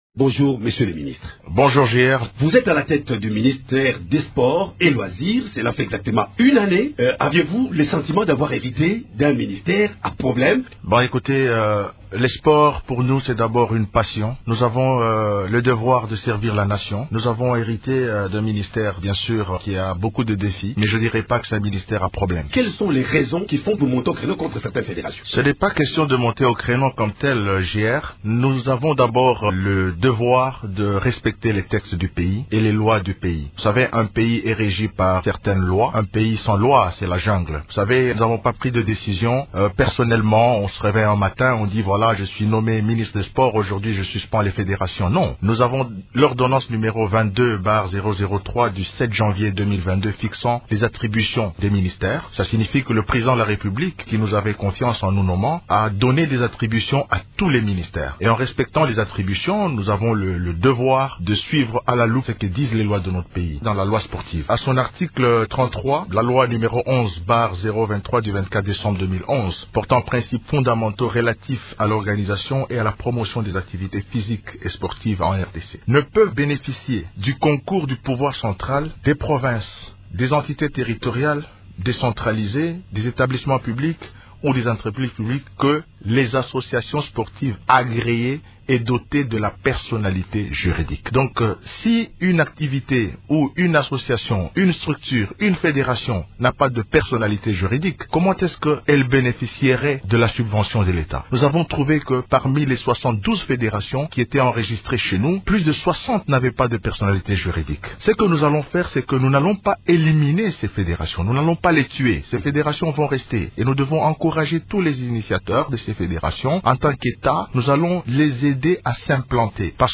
Invité de Radio Okapi, il reconnait avoir hérité d’un ministère ayant plusieurs défis.